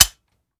weap_delta_fire_first_plr_01.ogg